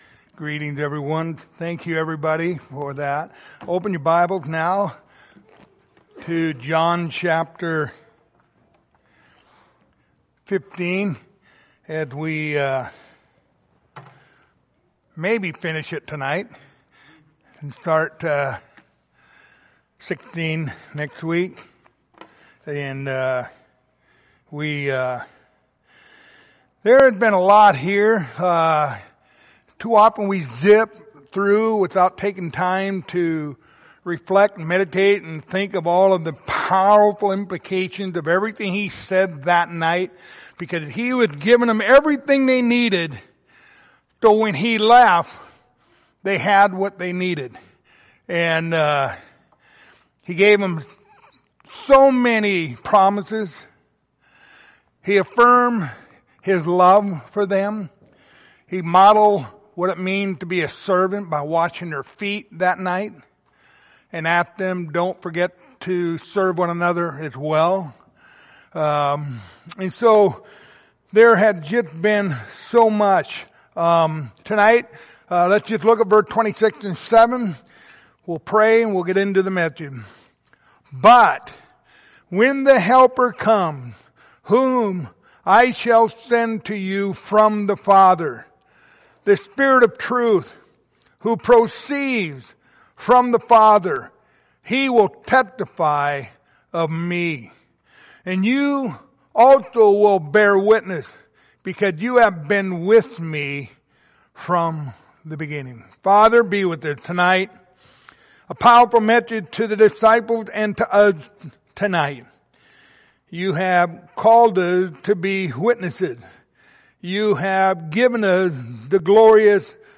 John 15:26-27 Service Type: Wednesday Evening Topics